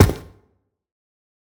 player foot.wav